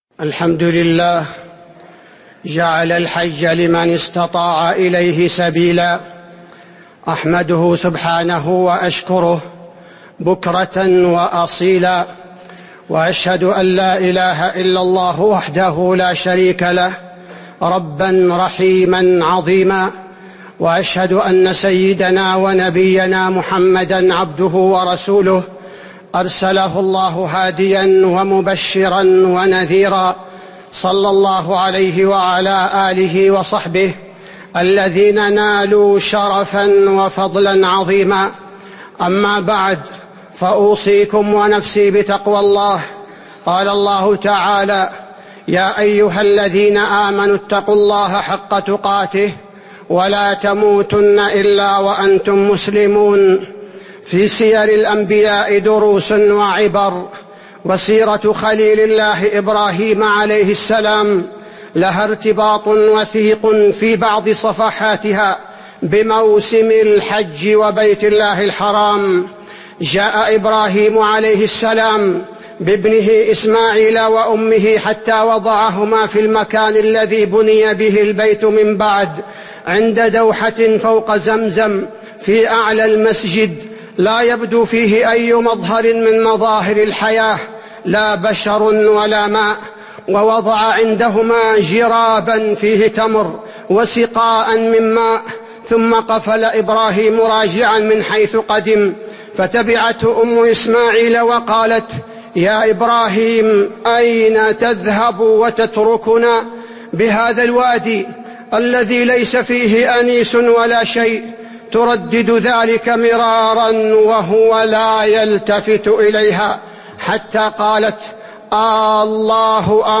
تاريخ النشر ٢٠ ذو القعدة ١٤٤٤ هـ المكان: المسجد النبوي الشيخ: فضيلة الشيخ عبدالباري الثبيتي فضيلة الشيخ عبدالباري الثبيتي إبراهيم عليه السلام والحج